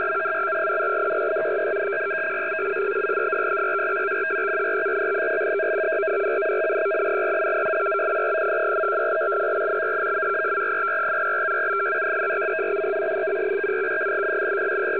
MCVFT-systems (Multichannel VFT)
CIS 3 x 144 Bd